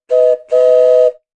长笛 " 粘土鸟长笛2
描述：两个粘土鸟笛（大小不同）放在嘴里一起吹，发出两个音。
Tag: 粘土 口哨